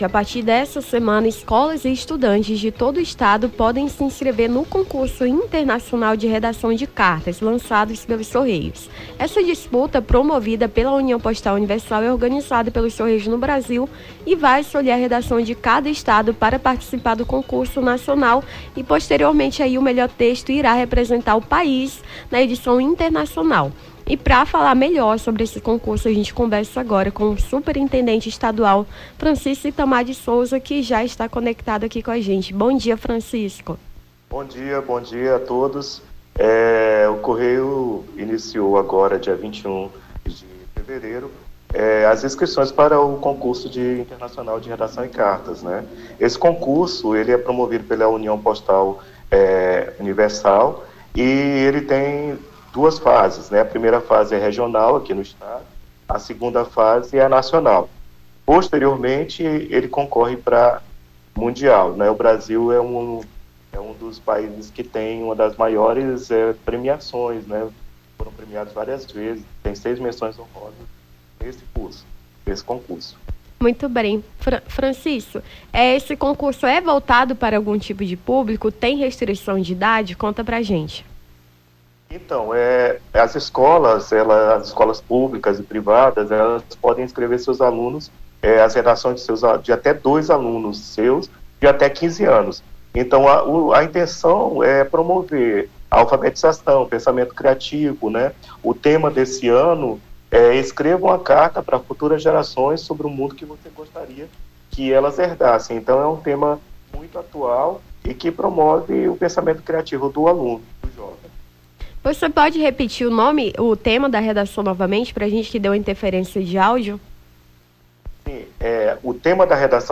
Nome do Artista - CENSURA - ENTREVISTA CONCURSO CORREIOS (23-02-24).mp3